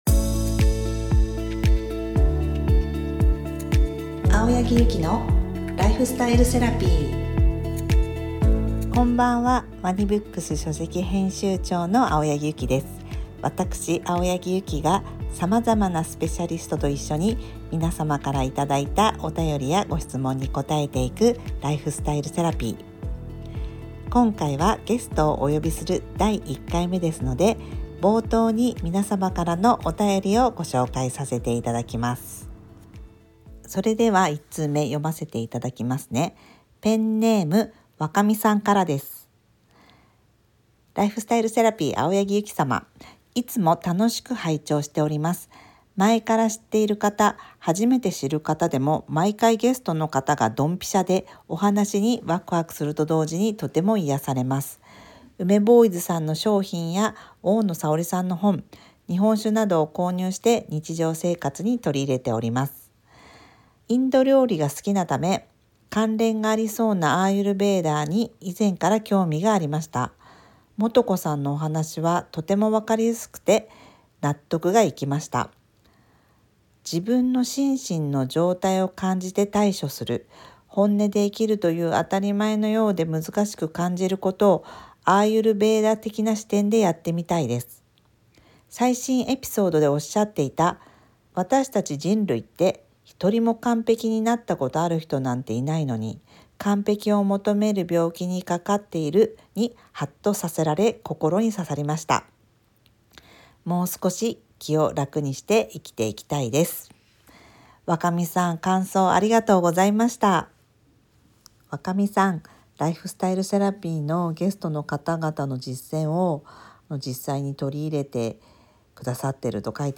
ライフスタイルセラピー、「the ANSWER」今週のゲストは、東京大学名誉教授の矢作直樹先生をお招きしました。